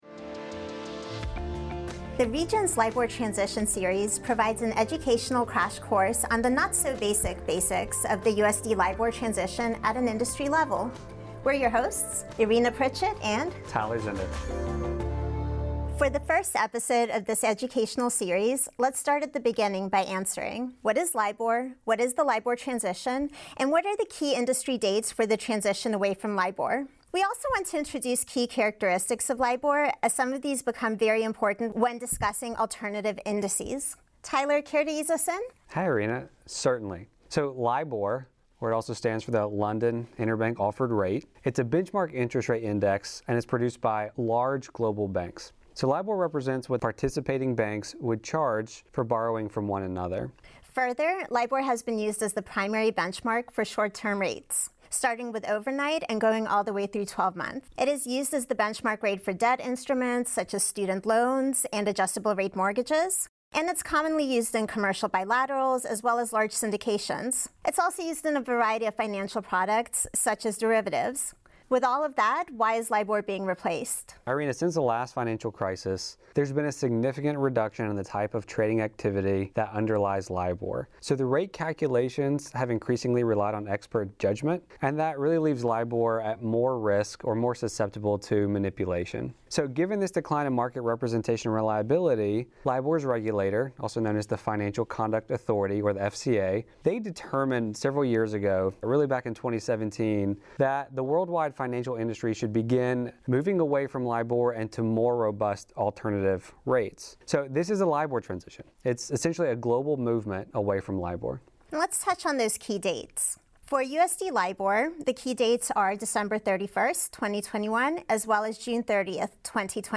The London Interbank Offered Rate (LIBOR) will be phased out this year, so Regions created an ongoing series of short podcasts explaining the foundations of the LIBOR transition and what you need to know to navigate the transition. Podcast hosts